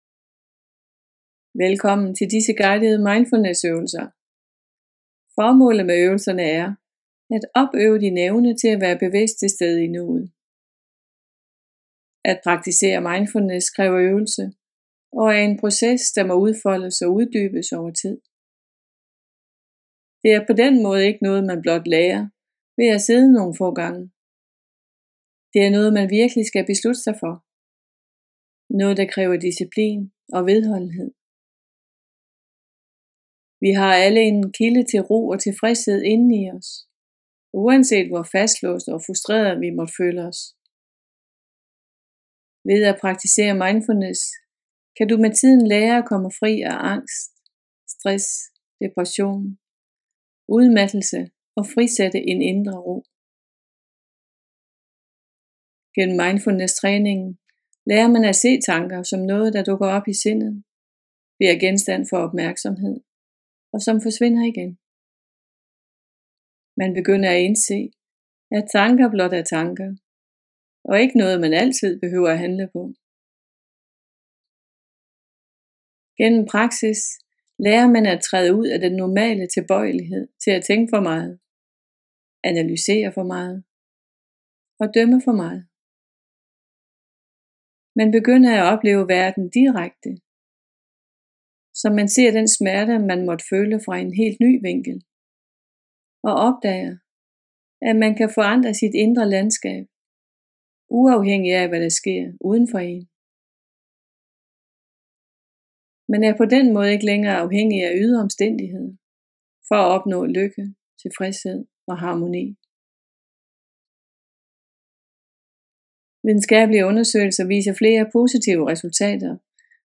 Guidede mindfulnessøvelser